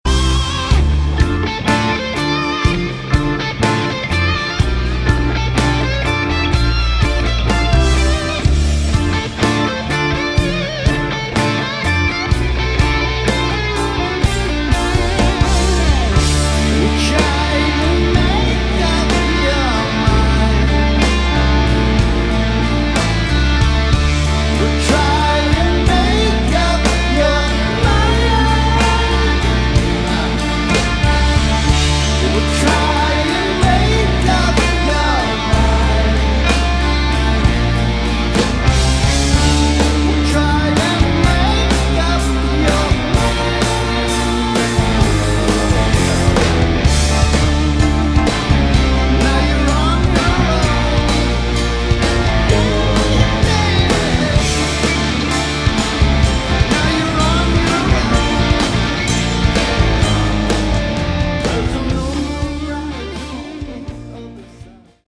Category: Melodic Rock / AOR
guitar
keyboards, vocals
bass
drums, vocals